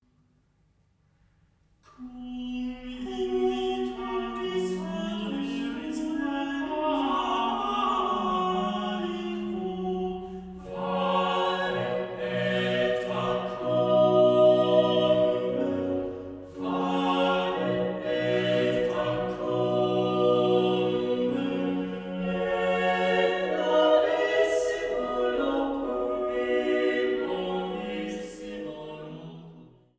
Leitung und Orgel